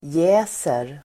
Uttal: [j'ä:ser]